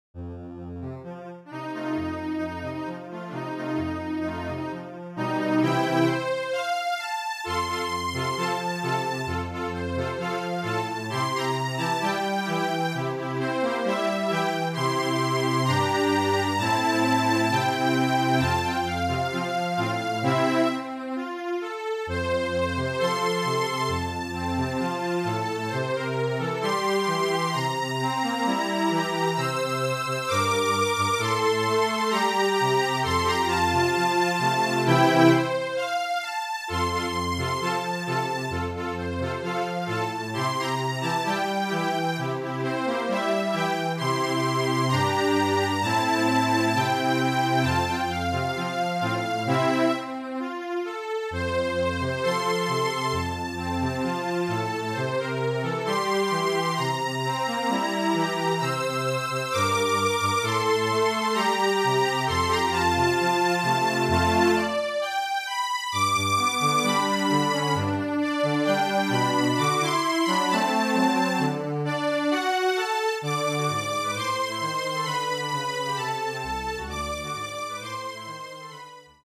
Flute, Violin and Cello (or Two Violins and Cello)
MIDI